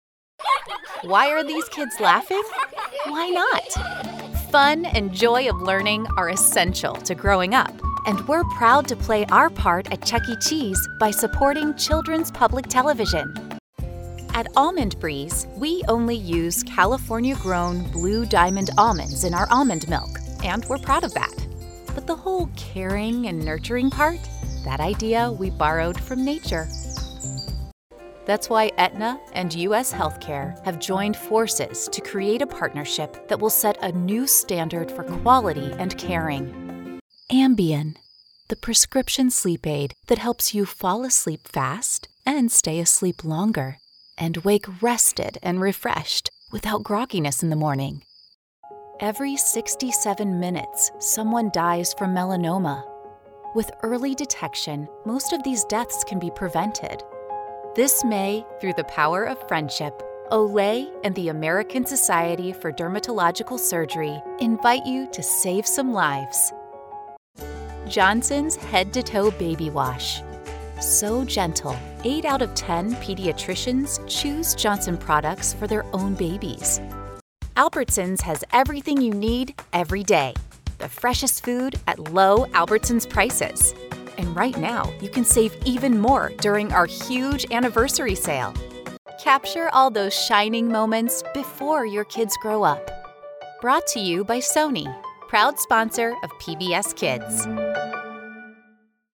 American Voice Over Talent
Adult (30-50)